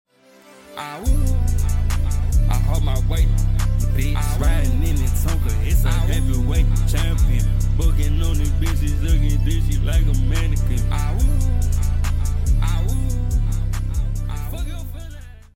awoo sound effects free download